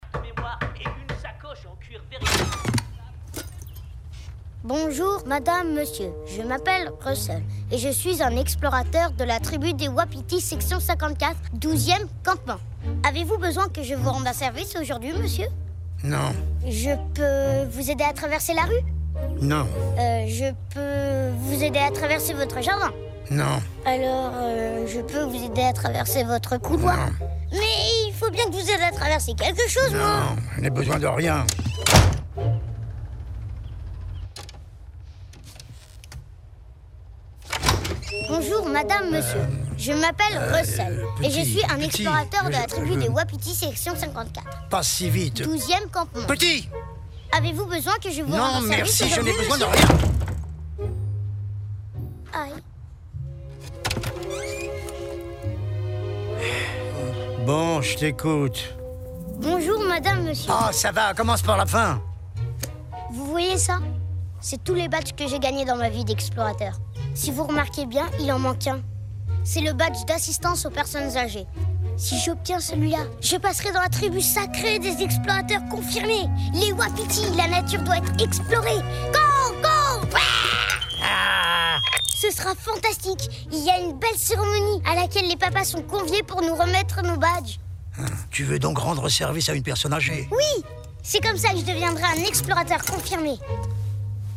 Un film d'animation surement.
C'est bien la voix de Charles Aznavour pourtant.